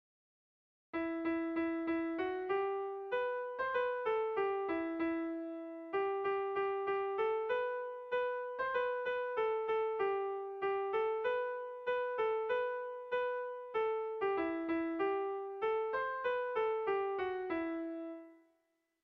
Gabonetakoa
Urdiain < Sakana < Iruñeko Merindadea < Navarre < Basque Country
Zazpiko berezia, 4 puntuz (hg) / Lau puntuko berezia (ip)
ABD